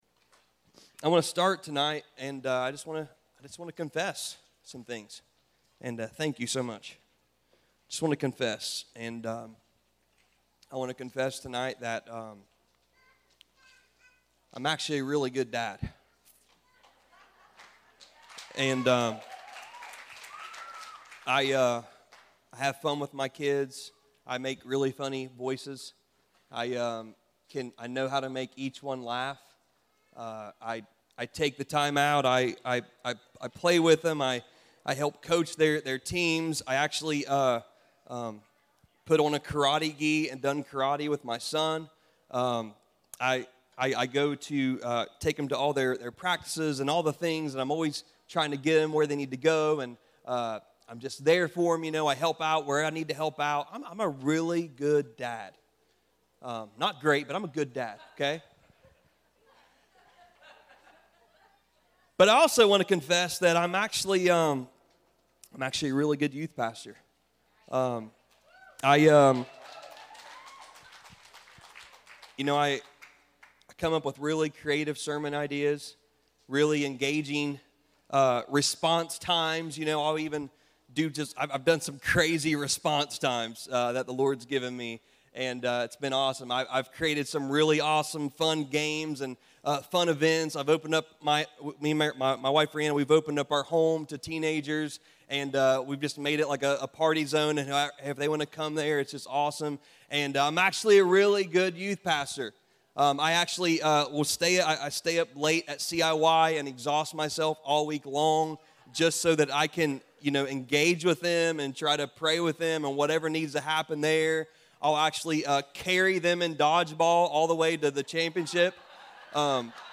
HOLD THE LINE - Stand Alone Messages ~ Free People Church: AUDIO Sermons Podcast